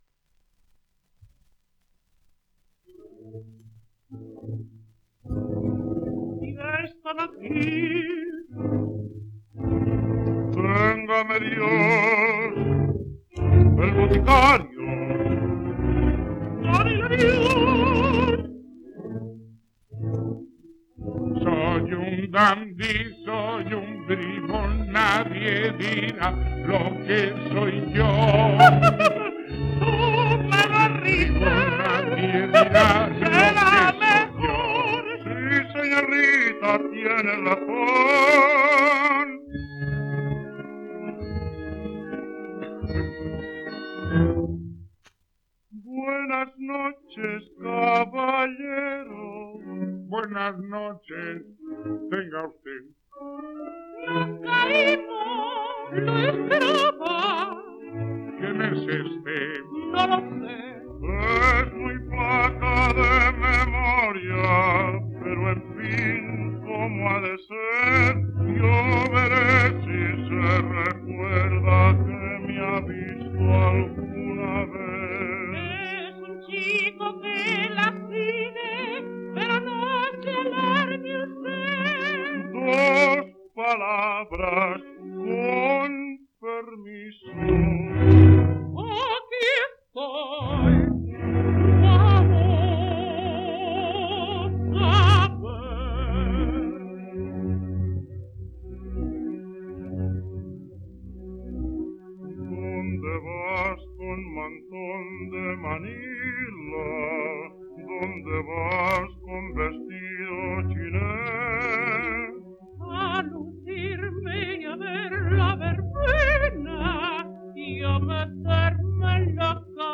3 discos : 78 rpm, mono ; 25 cm.
• Zarzuelas